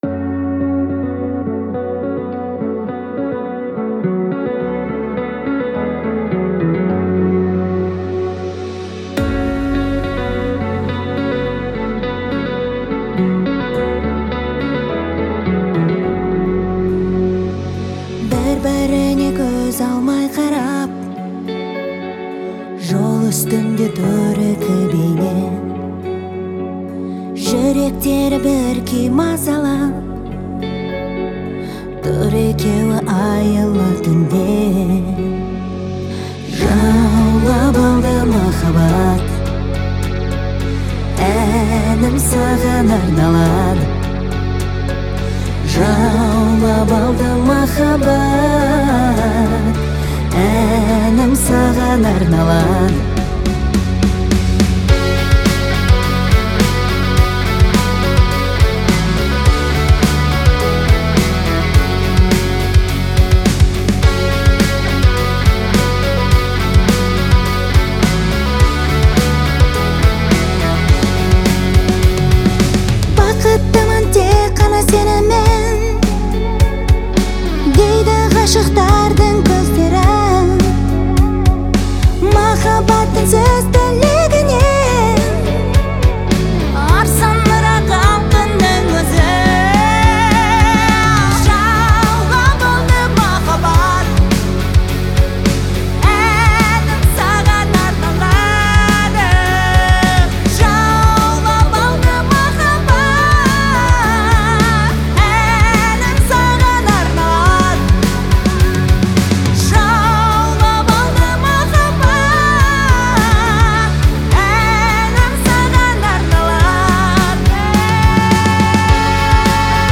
Категория: Казахские песни